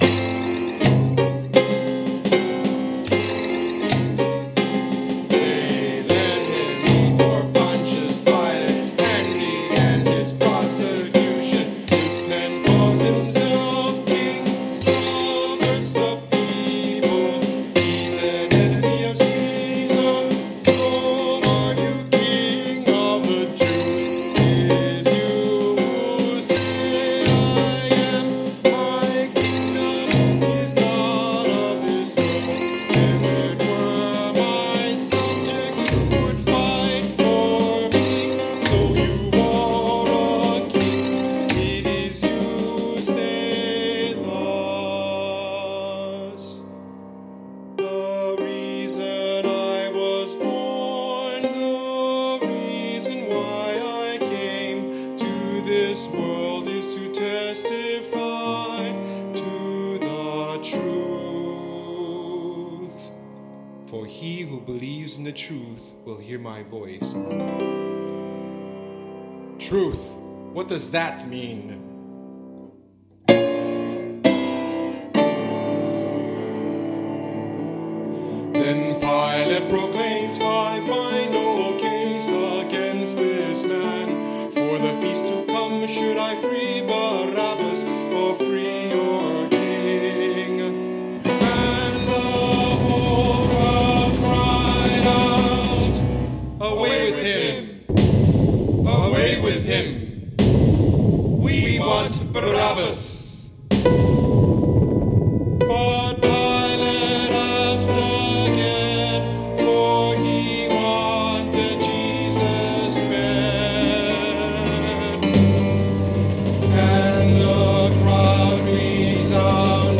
1984   following mp3's are "practice"